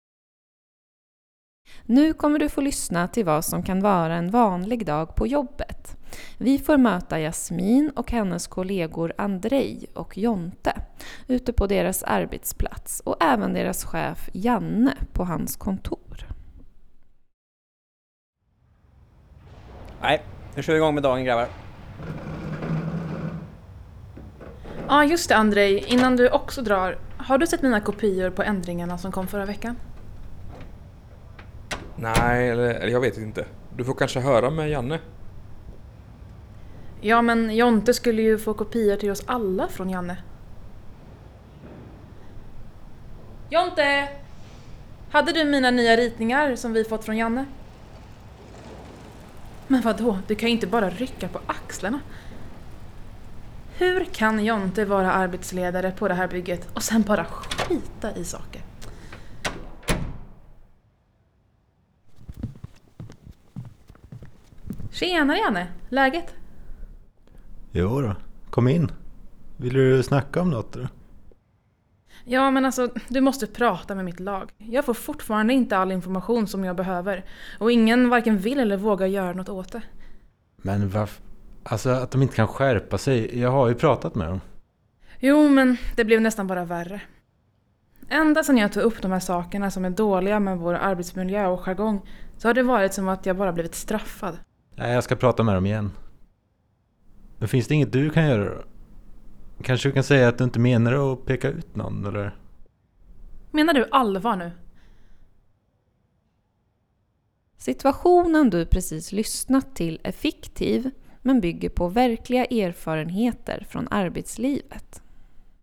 Övningen fokuserar på fem olika scenarion i radioteaterform som bygger på erfarenheter från svenska arbetsplatser.